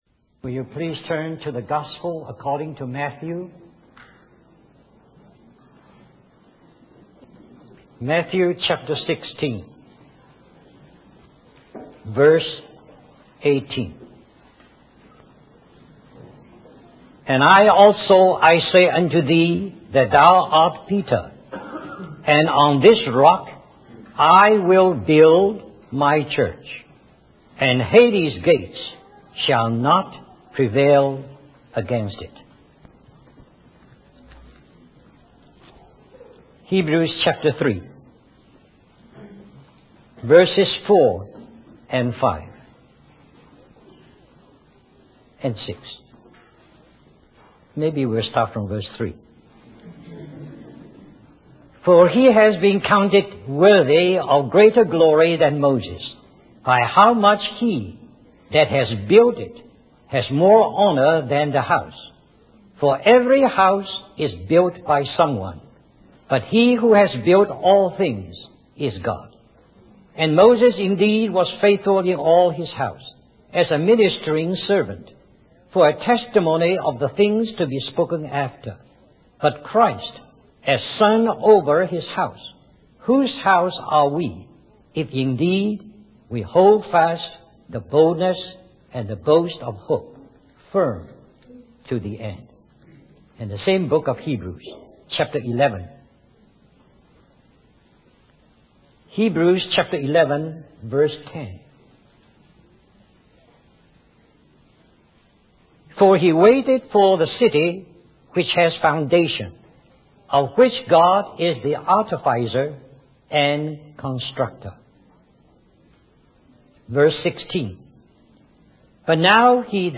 In this sermon, the preacher discusses the crucifixion of Jesus Christ and the significance of his cry, 'My God, My God, why have you forsaken me?'